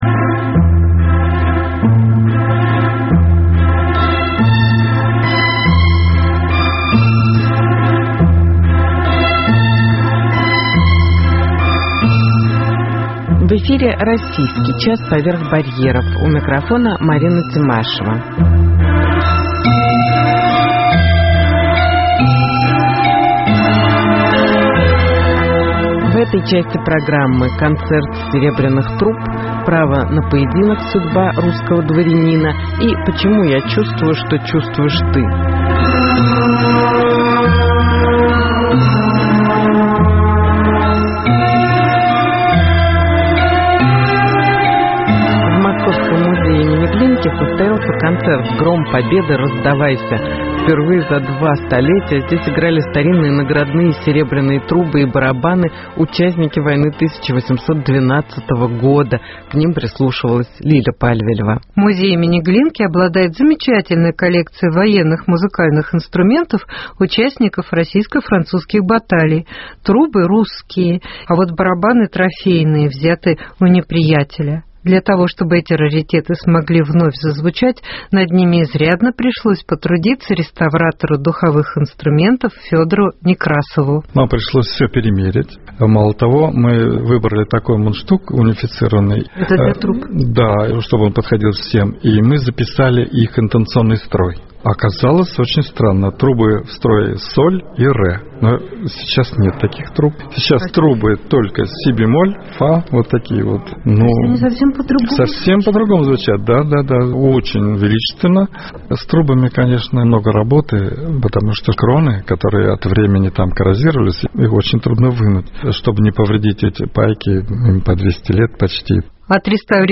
Концерт серебряных труб в музее Глинки